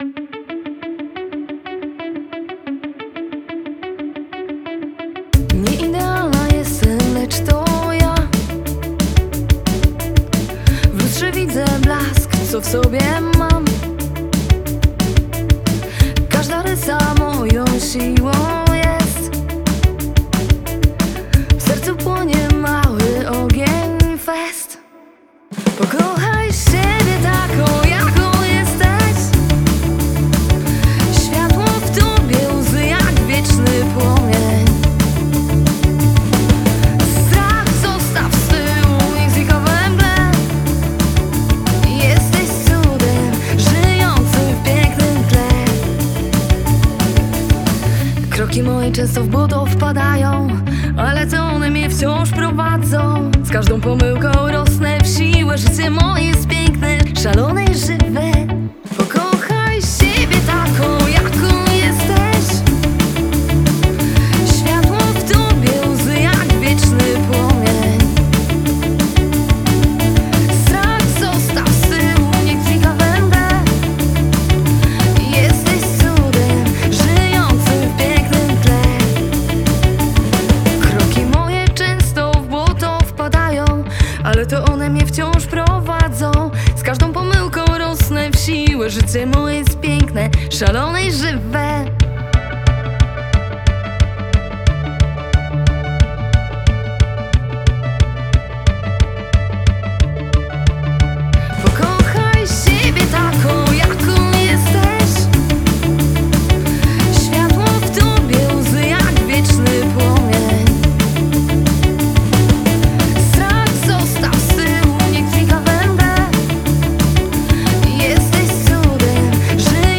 Singiel (Radio)
Posłuchajcie jej pop-rockowej nowości.